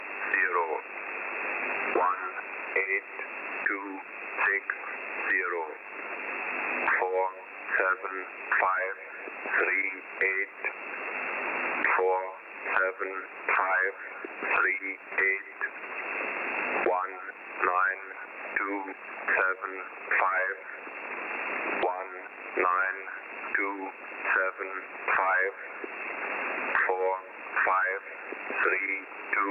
Number station E06
Recieved on 12119khz - 04-03-14